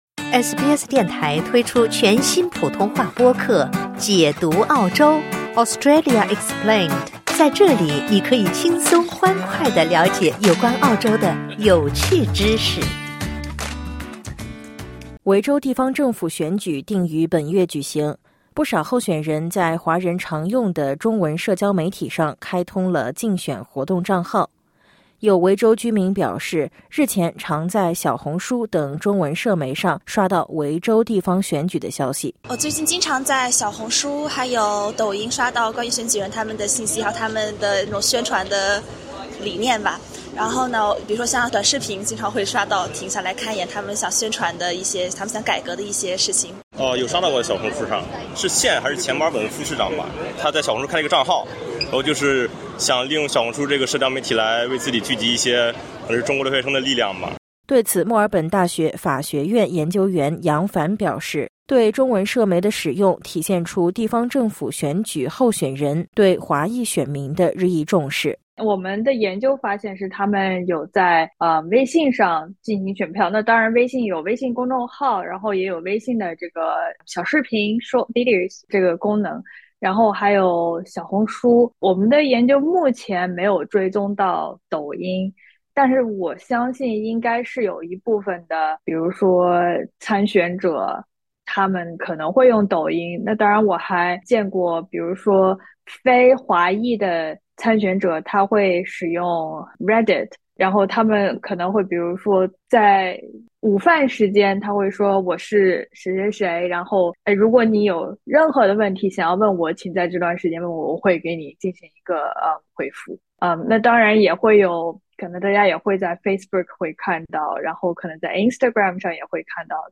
在候选人通过社交媒体为选举造势的同时，专家提醒选民谨慎辨别宣传信息，不要轻易被网络上的内容误导。点击音频，收听综合报道。